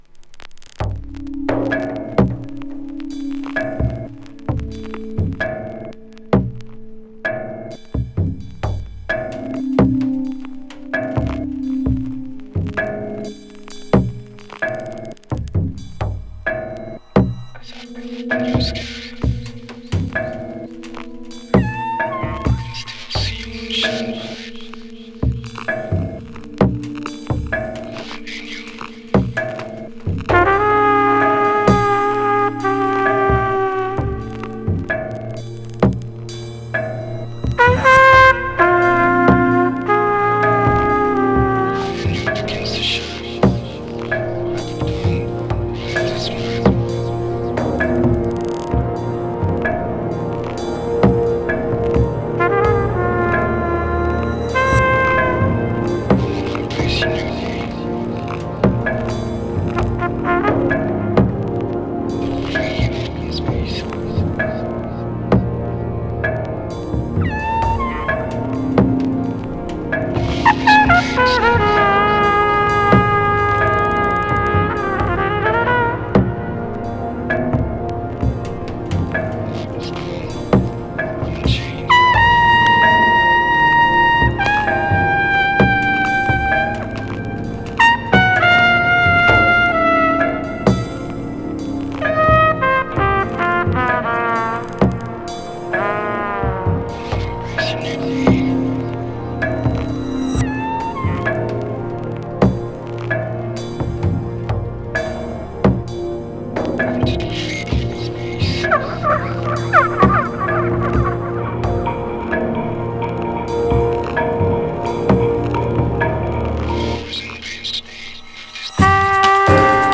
ジャズをメインにしたリミックスの数々。